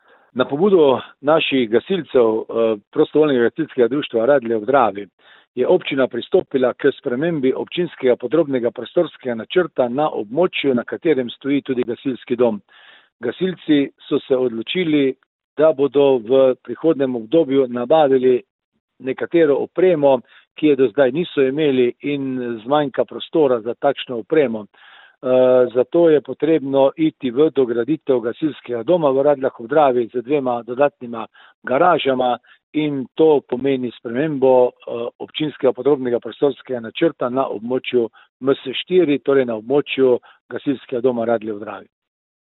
Občina Radlje ob Dravi je pristopila k spremembi in dopolnitvi občinskega podrobnega prostorskega načrta za območje, na katerem stoji gasilski dom, saj ga želijo radeljski gasilci dograditi. Župan Alan Bukovnik:
izjava gasilci .mp3